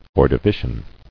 [Or·do·vi·cian]